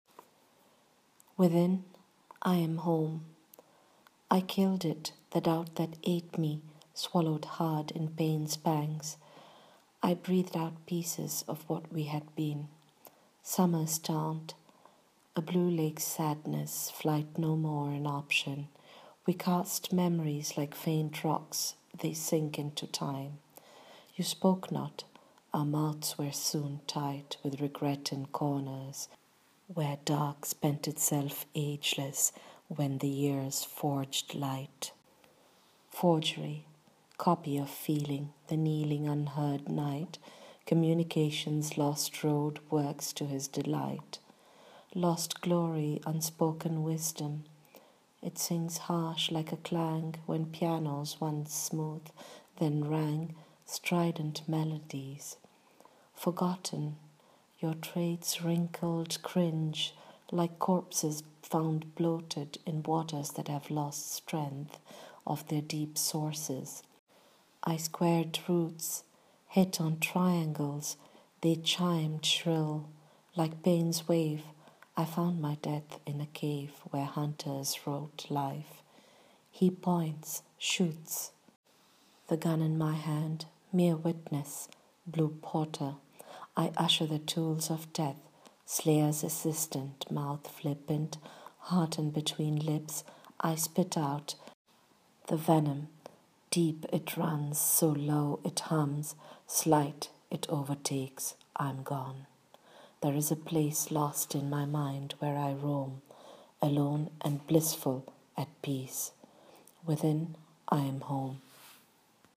Reading of my poem: